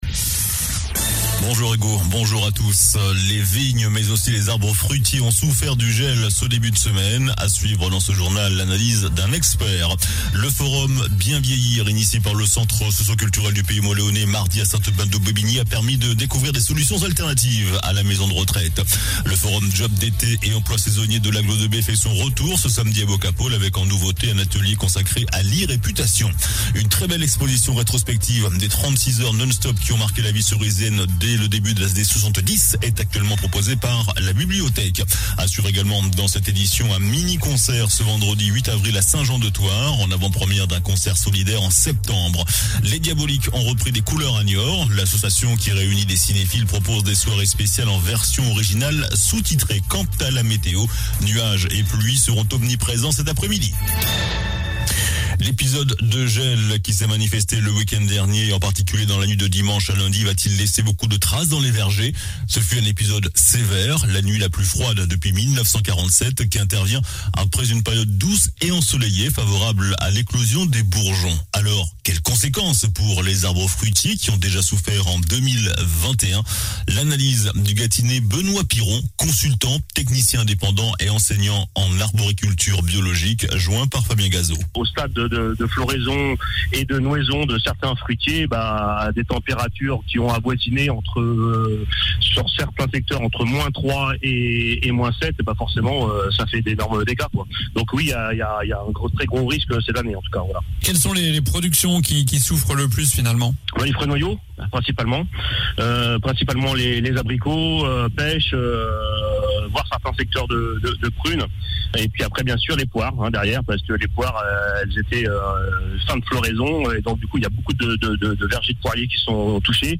JOURNAL DU JEUDI 07 AVRIL ( MIDI )